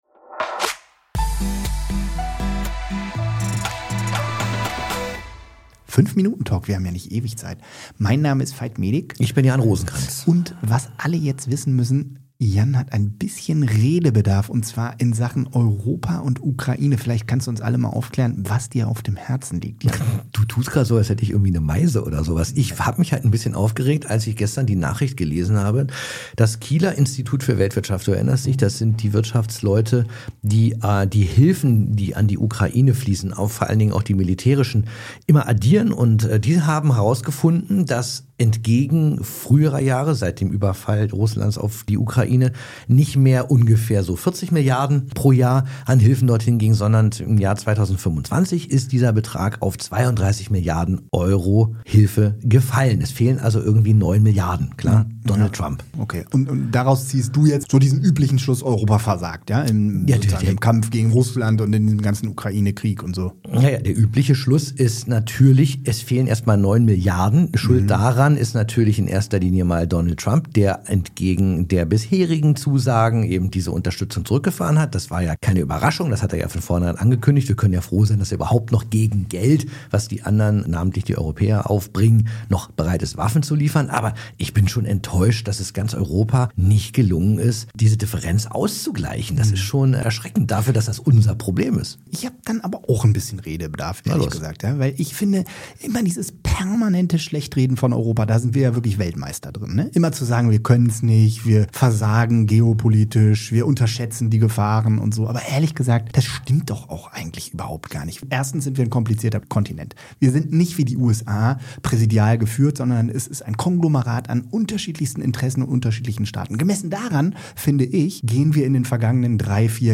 Die beiden Reporter streiten über die